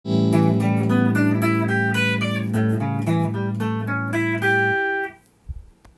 ７ｔｈ　ⅡーⅤ　コード例
A7（Em7/A7）